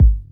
Kicks
Game Kick2.wav